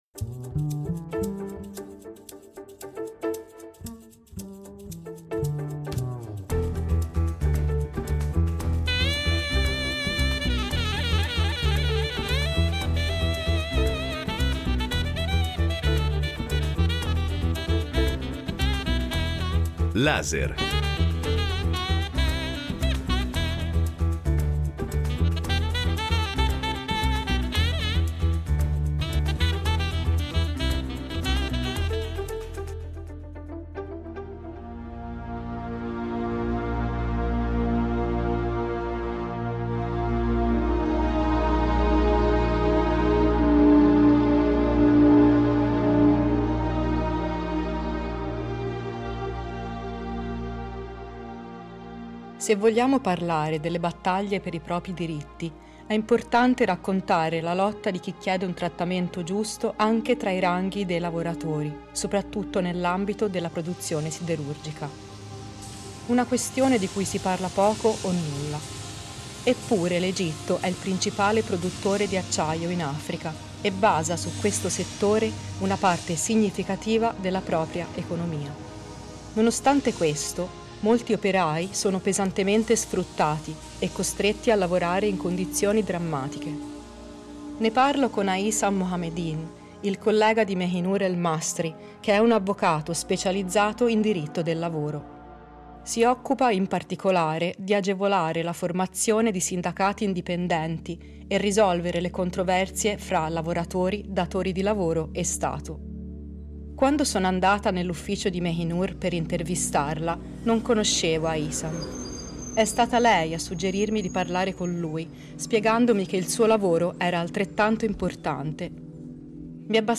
Torture, sparizioni e pena di morte: un reportage denuncia le violazioni dei diritti umani